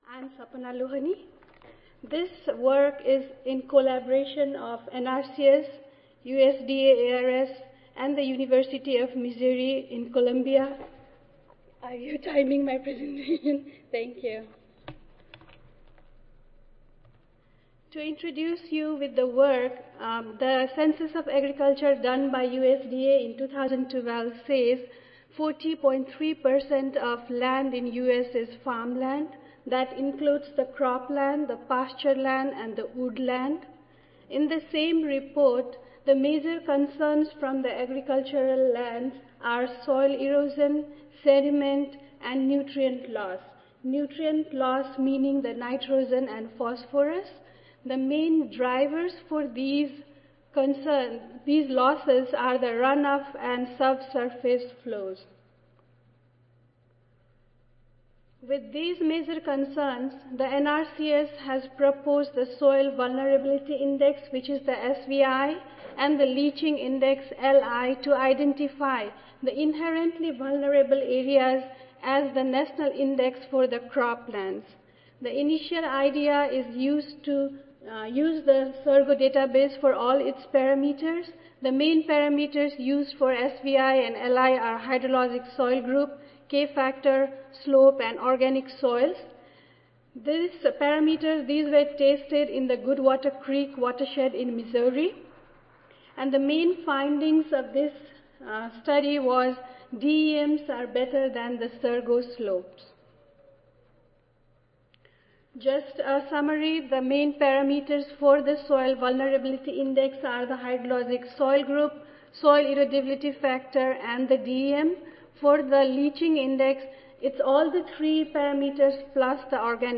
See more from this Division: SSSA Division: Soil and Water Management and Conservation See more from this Session: Soil and Water Management Conservation Oral III